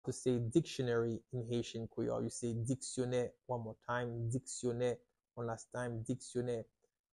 How to say "Dictionary" in Haitian Creole - "Diksyonè" pronunciation by a native Haitian Tutor
“Diksyonè” Pronunciation in Haitian Creole by a native Haitian can be heard in the audio here or in the video below:
How-to-say-Dictionary-in-Haitian-Creole-Diksyone-pronunciation-by-a-native-Haitian-Tutor-1.mp3